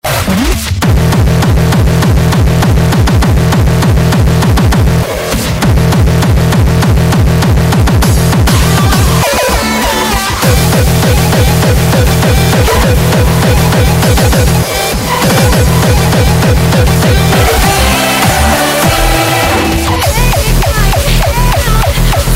Loud